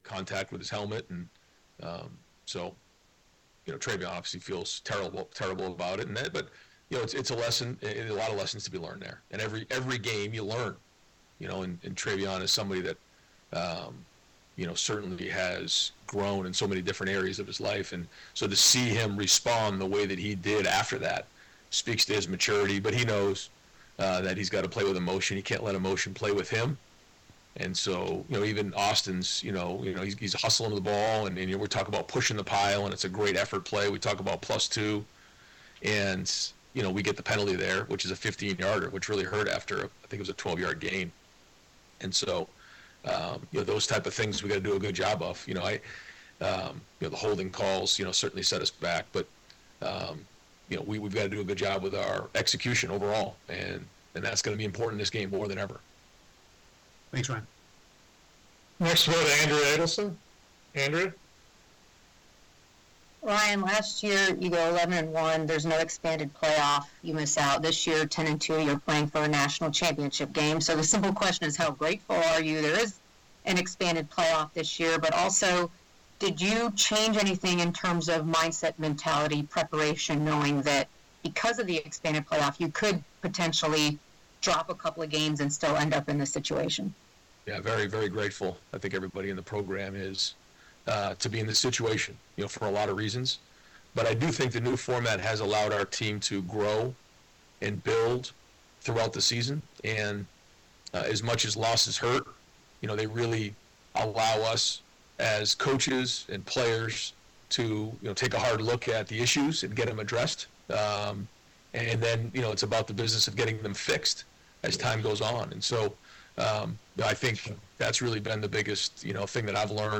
Ohio State Buckeyes National Championship Preview: Ryan Day Press Conference; Faces Irish with kick off at 7:45 p.m. at Mercedes-Benz Stadium Tonight in Atlanta
Ryan-Day-OSU-head-coach-National-Championship-Game-Preview-Press-Conference-Notre-Dame-January-2025.mp3